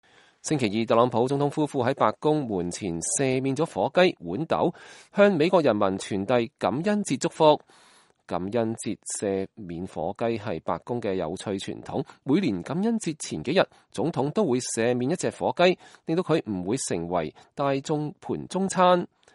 星期二，特朗普總統夫婦在白宮門前赦免了火雞“豌豆”，向美國人民傳遞感恩節祝福。